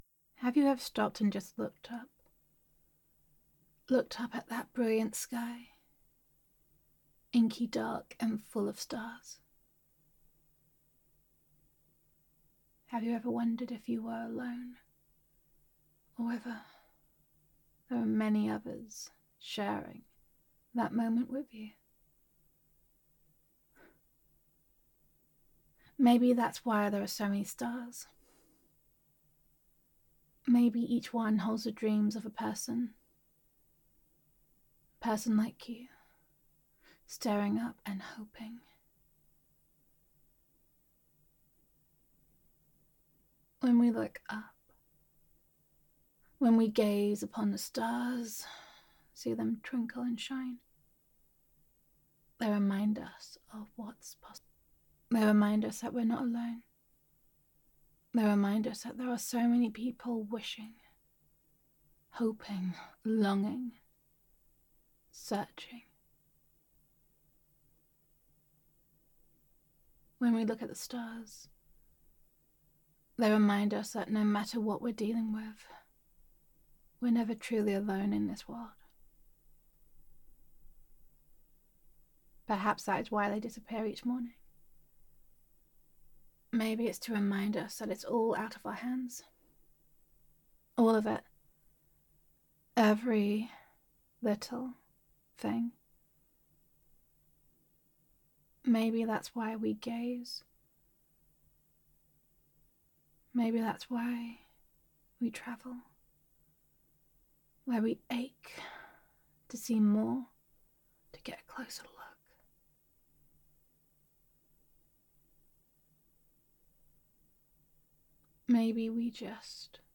[F4A] Reach Out [The Stars Shining Above][A Beautiful Tease][A Reminder That You Are Not Alone][Potential & Possibility][Gender Neutral][Caring Girlfriend Roleplay]